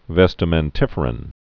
(vĕstə-mĕn-tĭfər-ən)